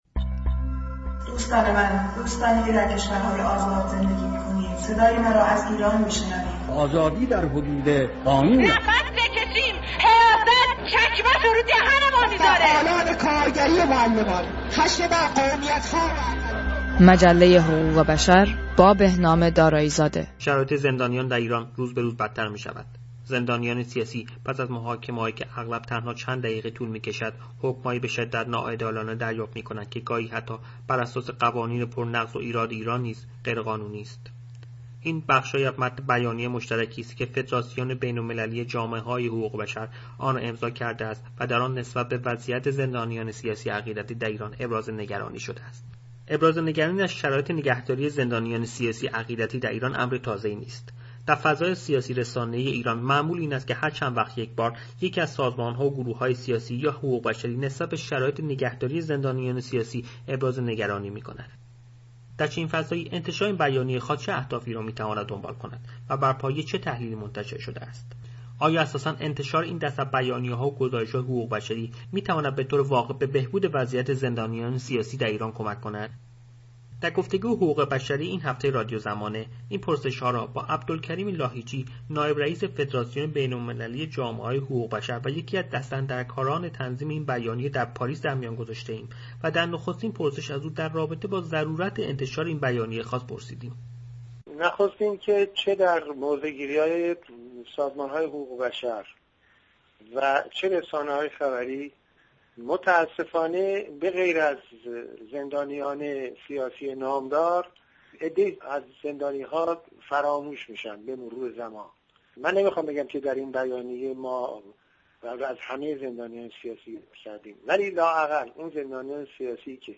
شرایط زندانیان سیاسی و عقیدتی در ایران در گفتگو با آقای عبدالکریم لاهیجی
شرایط زندانیان سیاسی و عقیدتی در ایران در گفتگو با آقای عبدالکریم لاهیجیرادیو زمانه در برنامه «مجله حقوق بشر» در روز جمعه ۱۳ مرداد ۱۳۹۱ (۳ آگوست ۲۰۱۲) با آقای عبدالکریم لاهیجی، نایب رییس فدراسیون بین المللی جامعه های حقوق بشر و رییس جامعه دفاع از حقوق بشر در ایران (عضو فدراسیون) در باره علت انتشار بیانیه مشترک این دو سازمان و دارنده جایزه صلح نوبل خانم شیرین عبادی گفتگو کرد.